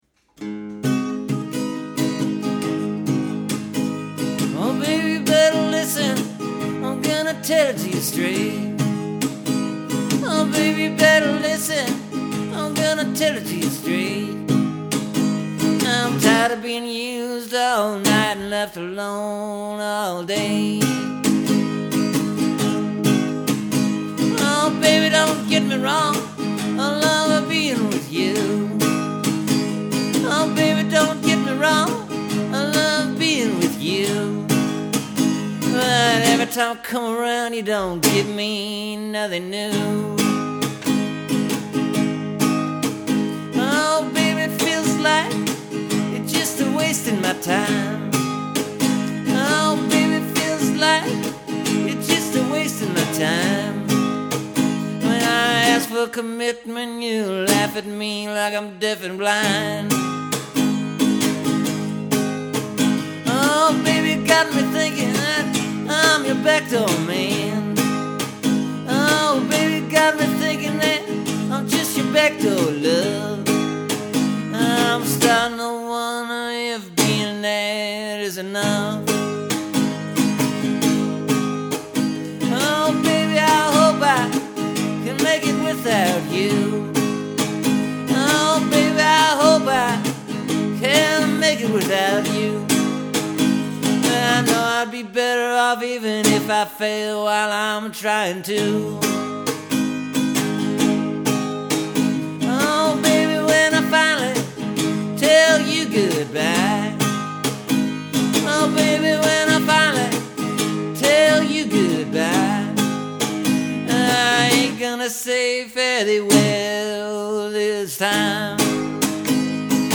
It’s kinda a blues-type-thing. I recorded it in one take, so there’s a couple mistakes in there and after I recorded it I made a few minor edits to a few of the lines just to clean up the phrasing.